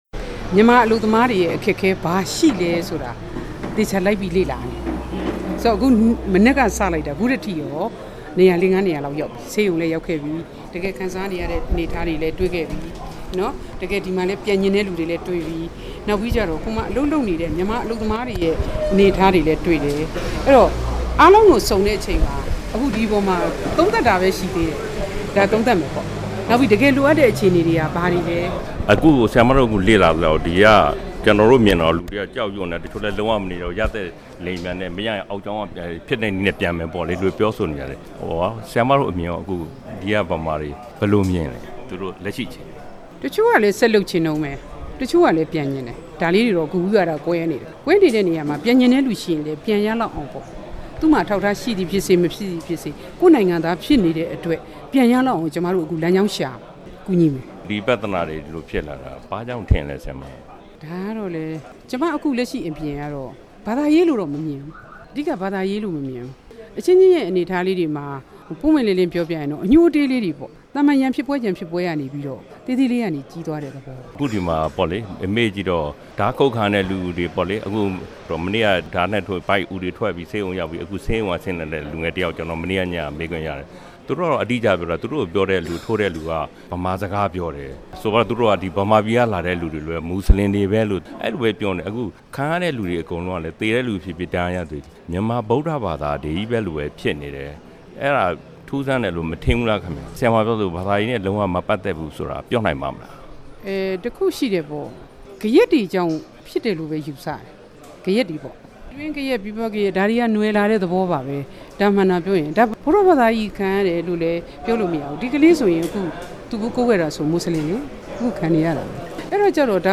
မလေးရှားကို ရောက်ရှိနေတဲ့ အလုပ်သမား ဒုဝန်ကြီးနှင့် RFA တွေ့ဆုံမေးမြန်းချက်